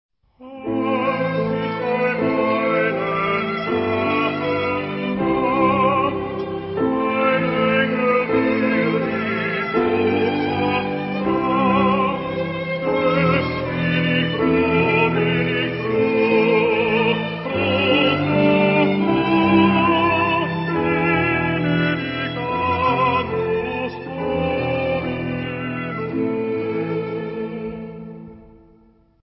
Género/Estilo/Forma: Sagrado
Carácter de la pieza : religioso ; contemplativo
Tipo de formación coral: SATB  (4 voces Coro mixto )
Instrumentos: Instrumento melódico (ad lib) ; Teclado (1)
Tonalidad : la menor